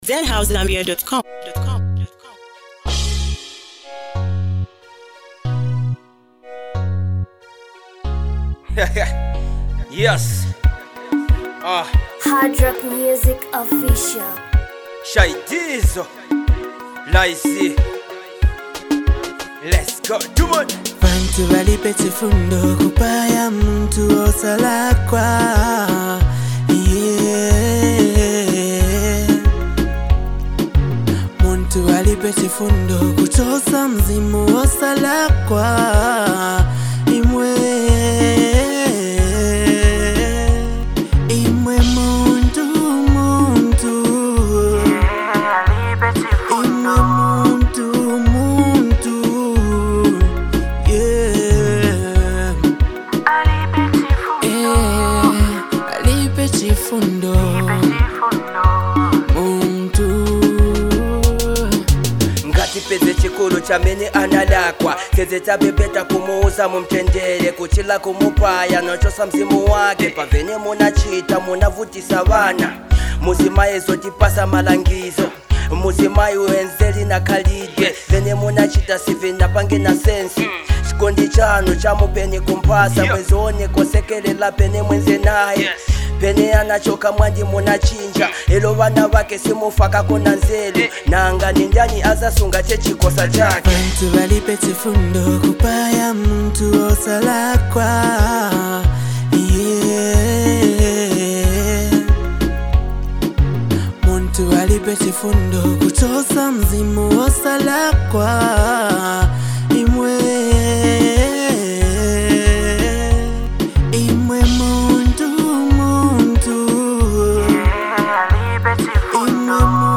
emotional and relatable track